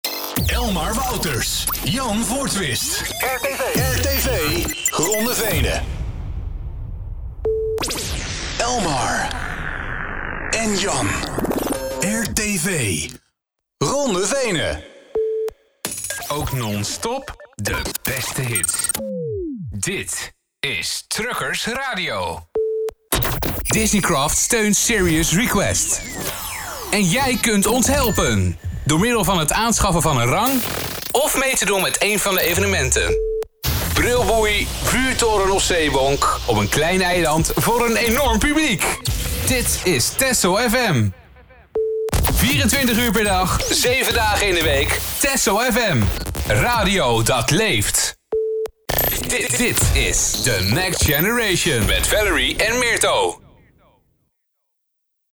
Diverse sweepers